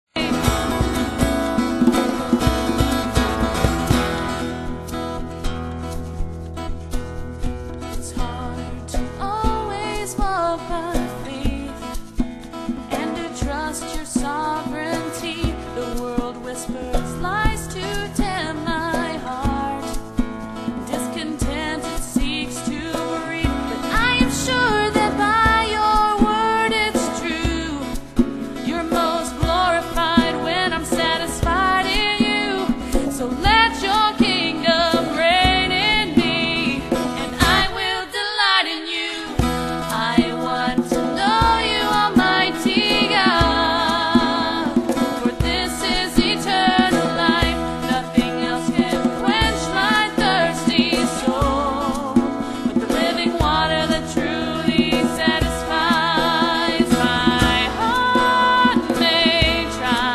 It's very amateur, so don't expect a Grammy-worthy performance.
Guitar
Percussion